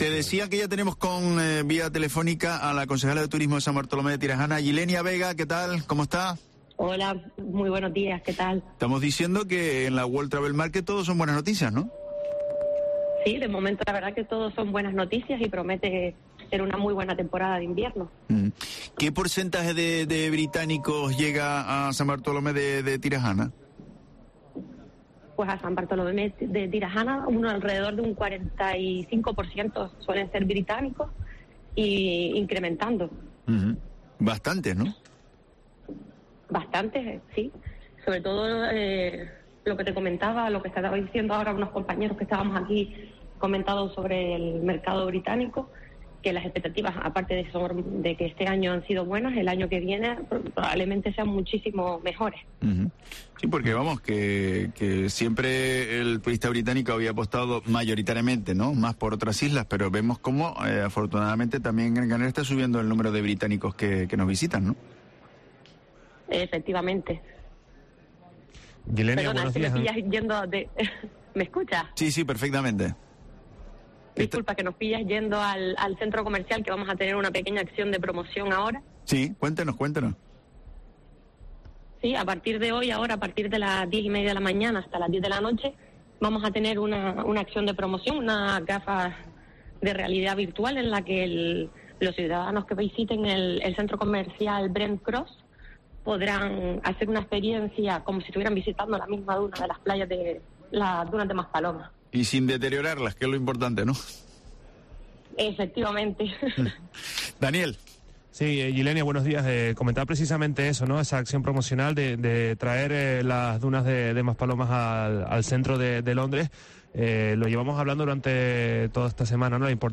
Ylenia Vega, concejala de Turismo San Bartolomé de Tirajana
La concejala de Turismo de San Bartolomé de Tirajana, Ylenia Vega, ha explicado en los micrófonos de Herrera en Cope Canarias que la gran acción de la localidad para atraer turistas británicos es recurrir a la inteligencia artificial "para que el visitante sienta una experiencia inmersiva" de sus playas admitiendo que el turismo de las islas suponen el 45 %" de todos los visitantes al municipio."